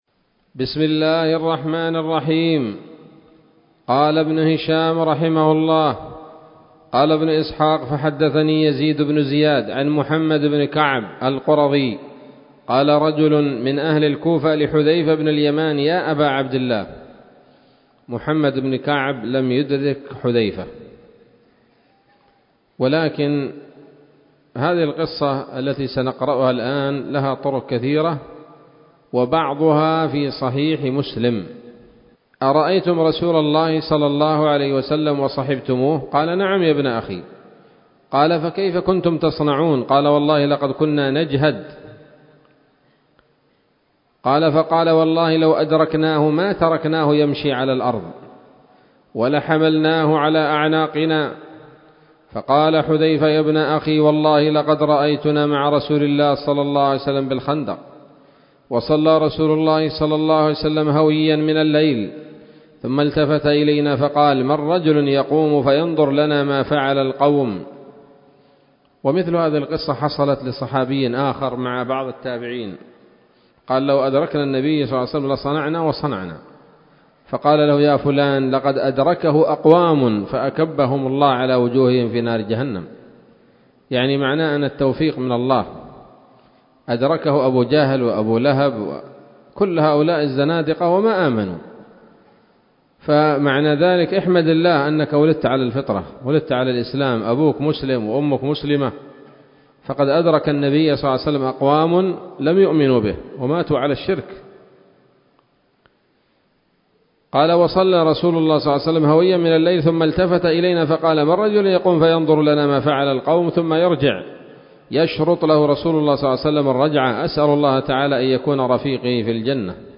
الدرس الرابع بعد المائتين من التعليق على كتاب السيرة النبوية لابن هشام